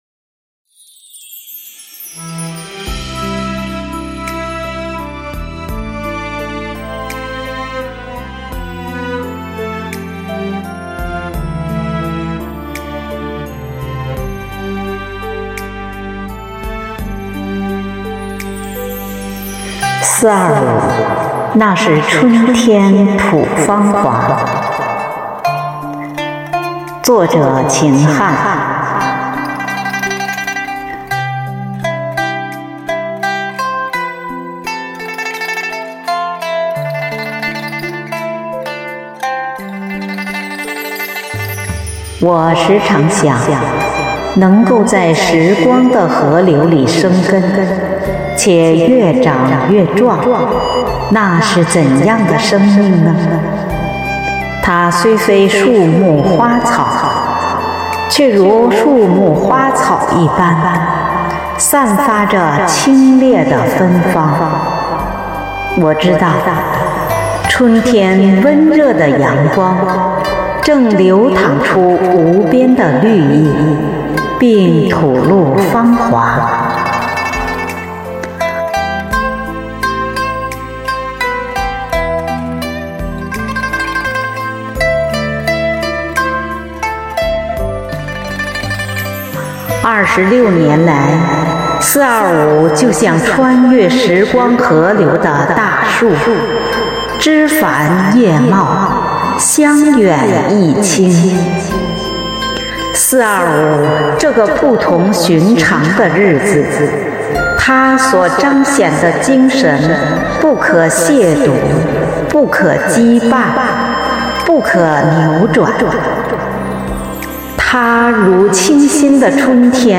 配樂朗誦（音頻）：散文短章：「四.二五」，那是春天吐芳華